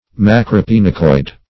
Search Result for " macropinacoid" : The Collaborative International Dictionary of English v.0.48: Macropinacoid \Mac`ro*pin"a*coid\, n. [Macro- + pinacoid.]